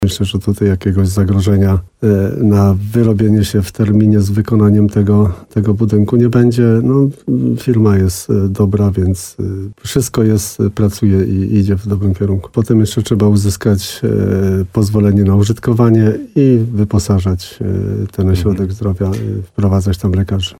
Wójt gminy Rytro Jan Kotarba przypomina, że wykonawca ma czas na postawienie budynku do końca tego roku.